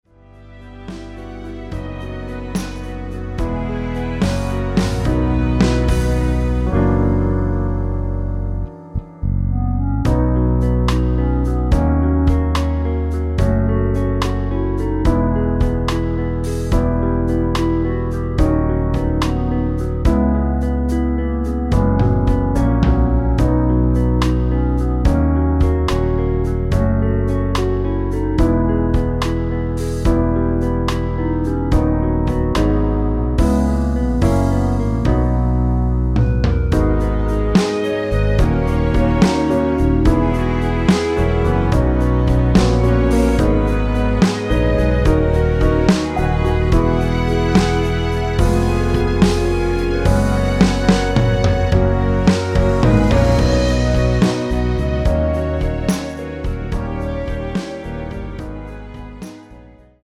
원키에서(-1)내린 (1절앞+후렴)으로 진행되는 멜로디 포함된 MR입니다.(미리듣기 확인)
앞부분30초, 뒷부분30초씩 편집해서 올려 드리고 있습니다.
중간에 음이 끈어지고 다시 나오는 이유는